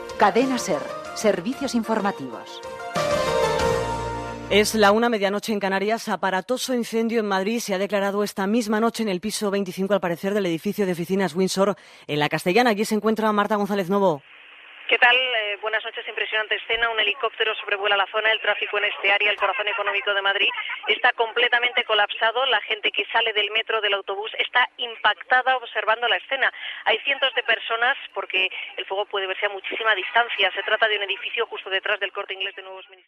Careta del programa, hora, connexió per informar de l'incendi de la torre Winsor, a Madrid
Informatiu